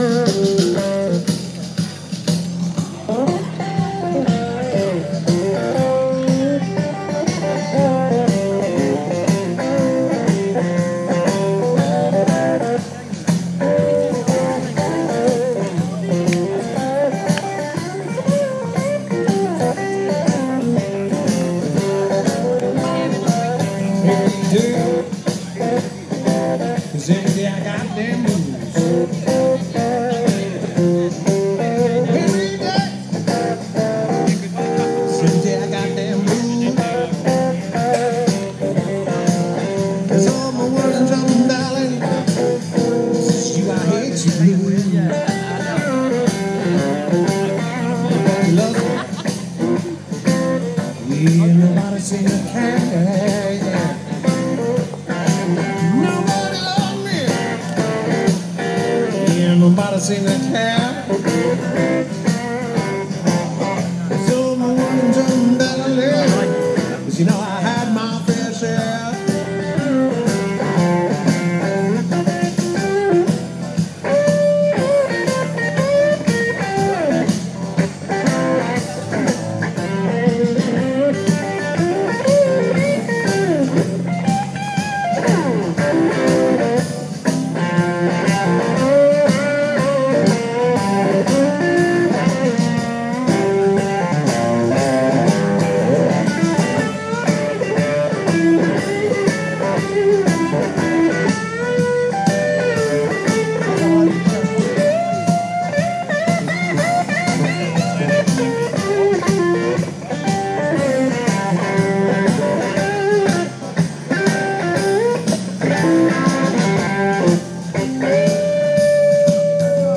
Chanced upon this guy playing the blues in Winchester.